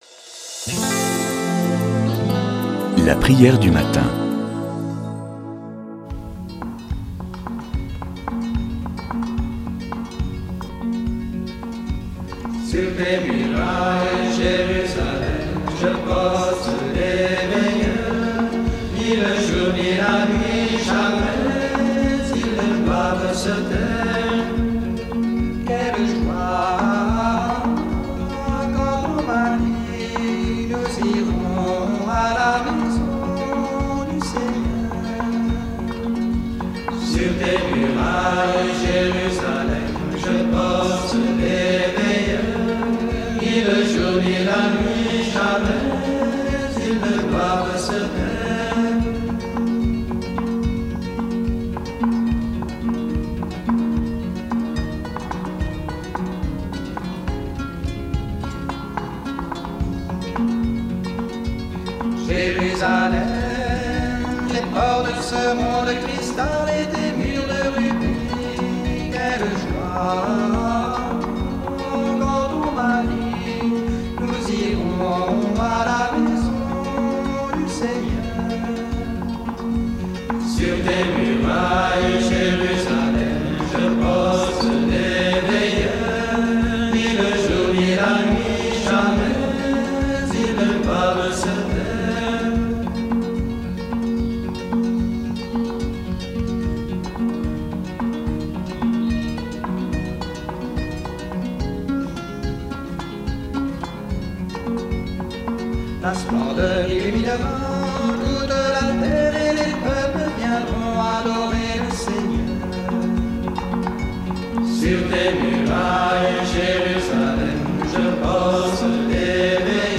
Une émission présentée par Groupes de prière